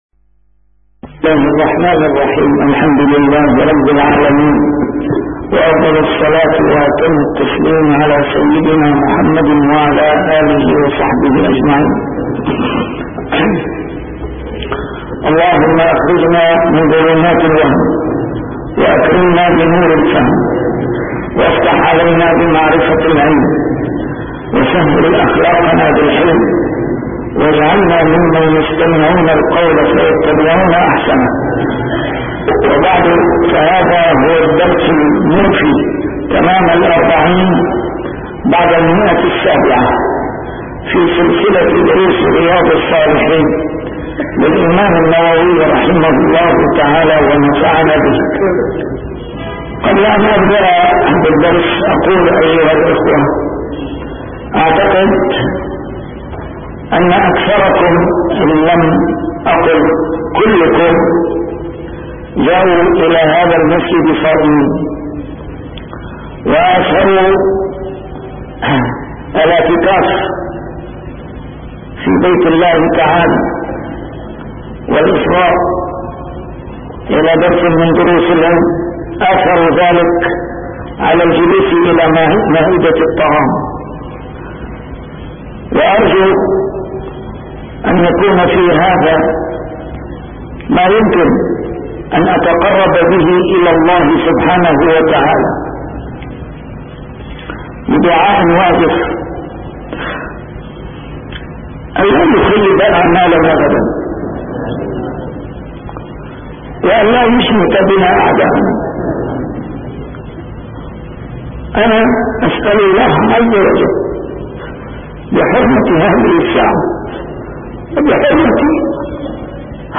A MARTYR SCHOLAR: IMAM MUHAMMAD SAEED RAMADAN AL-BOUTI - الدروس العلمية - شرح كتاب رياض الصالحين - 740- شرح رياض الصالحين: القدوم على أهله نهاراً ما يقول إذا رجع ورأى بلدته